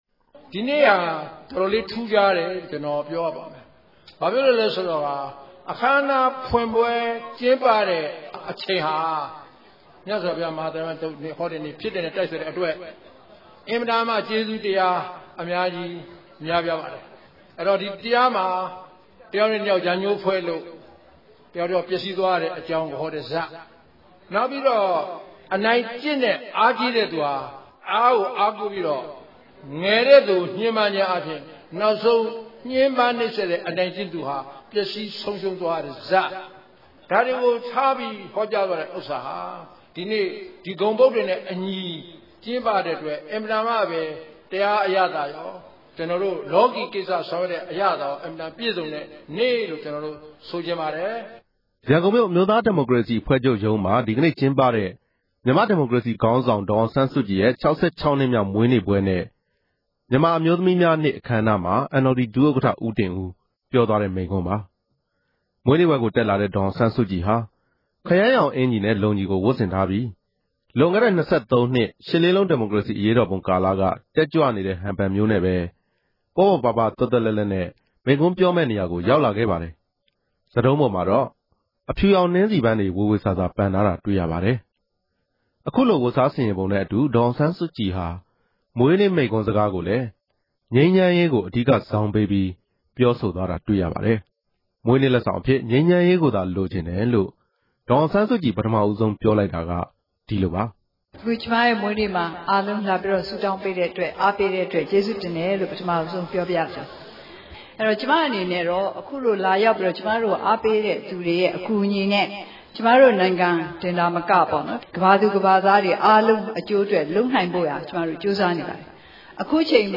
ရန်ကုန်မြို့ NLD ဌာနချုပ်မှာ ကျင်းပတဲ့ မွေးနေ့ပွဲ အခမ်းအနားမှာ ဒေါ်အောင်ဆန်းစုကြည် ပထမဆုံး ပြောကြားလိုက်တဲ့ စကားပါ။